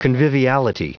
Prononciation du mot conviviality en anglais (fichier audio)
Prononciation du mot : conviviality